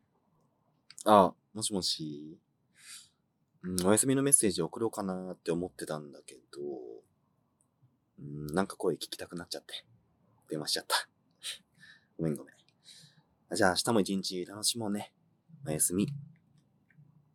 カイ おやすみボイス
カイおやすみボイス.wav